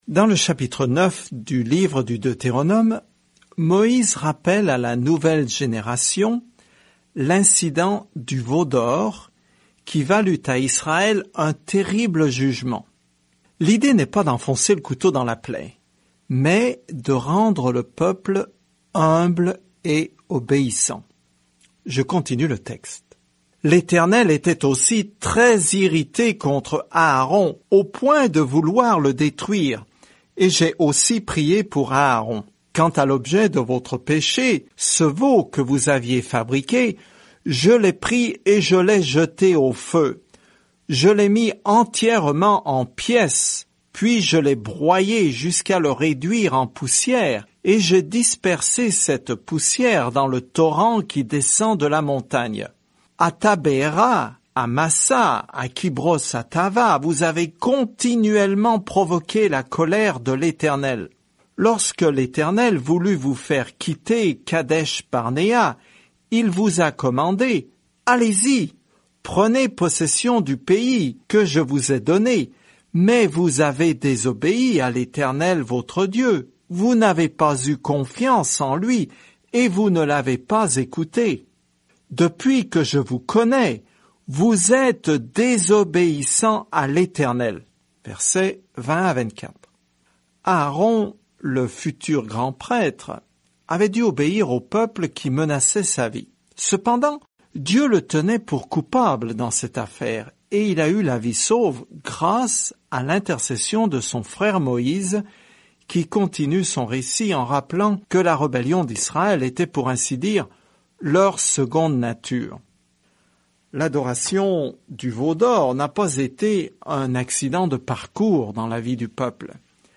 Écritures Deutéronome 9:20-29 Deutéronome 10 Deutéronome 11 Deutéronome 12:1-5 Jour 6 Commencer ce plan Jour 8 À propos de ce plan Le Deutéronome résume la bonne loi de Dieu et enseigne que l’obéissance est notre réponse à son amour. Parcourez quotidiennement le Deutéronome en écoutant l’étude audio et en lisant certains versets de la parole de Dieu.